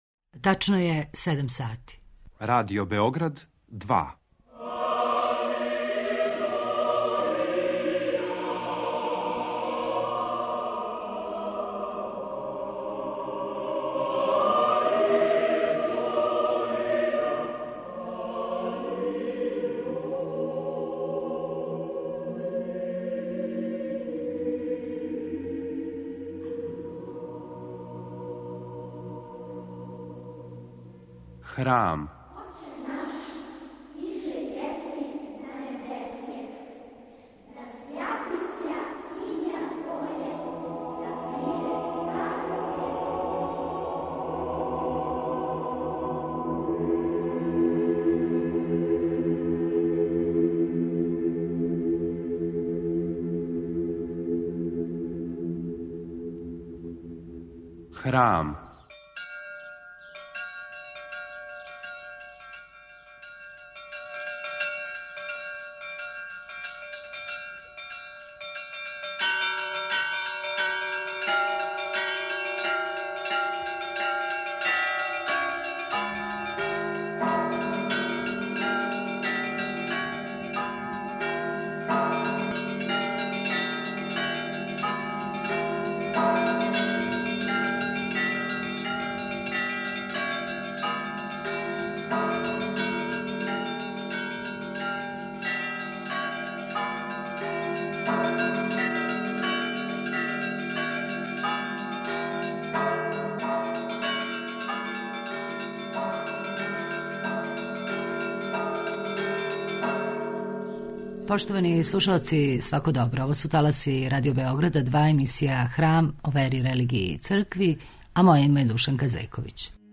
Протекле седмице, у крипти Храма Светог Саве у Београду представљена је збирка прича "Преко прага", владике захумско-херцеговачког и приморског Григорија. О књизи су говорили академик Љубомир Симовић, владика будимљанско-никшићки Јоакије и аутор, а за данашњи Храм , упитали смо владику Григорија преко ког прага још прелази својим најновијим књижевним делом.